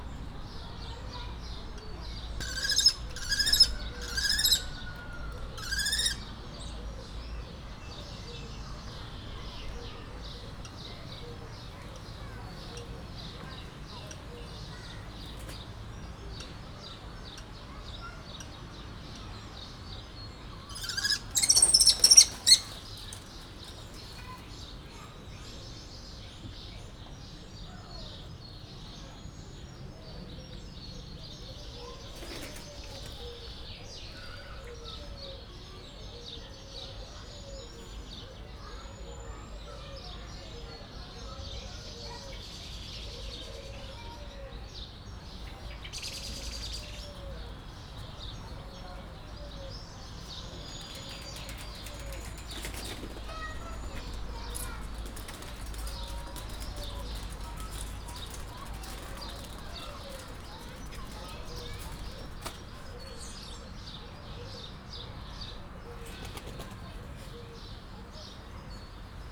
macskabagoly
macskabagoly_vorosvercsekonfliktusfele01.09.WAV